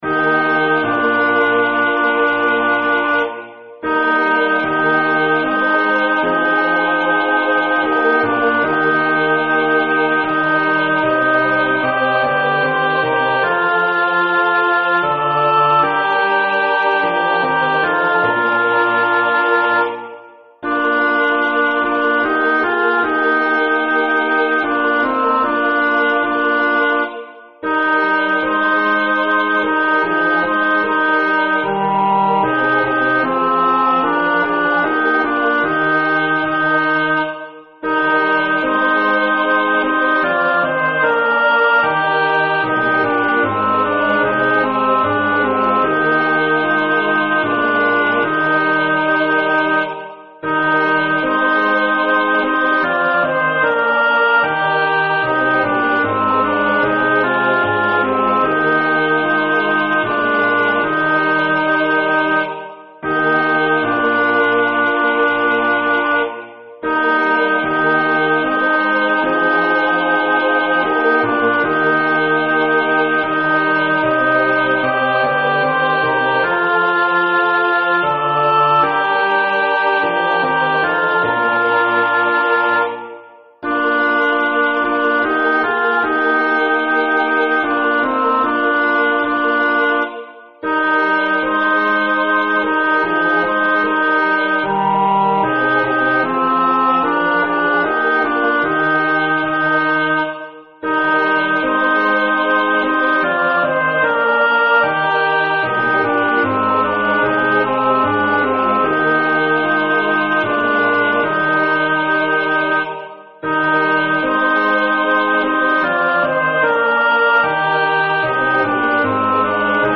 Junger kammerchor düsseldorf Dir